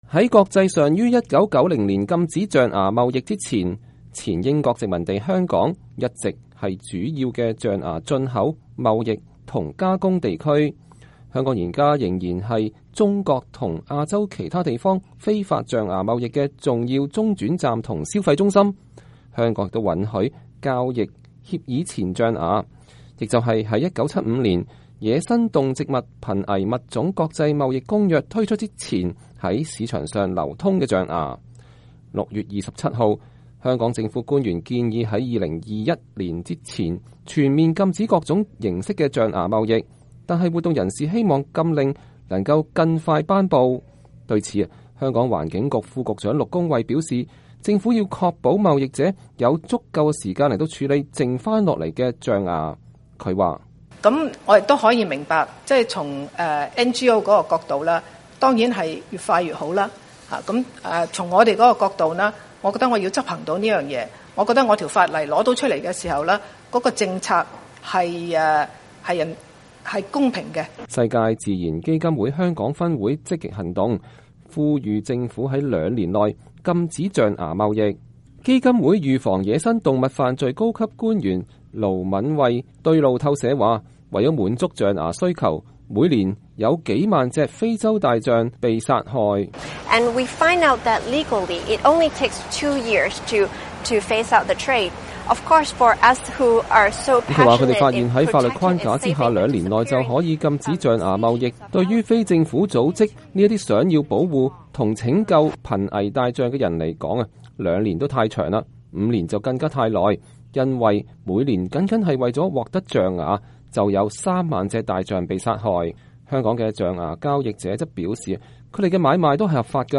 2016-06-28 美國之音視頻新聞: 香港計劃在五年內全面禁止象牙貿易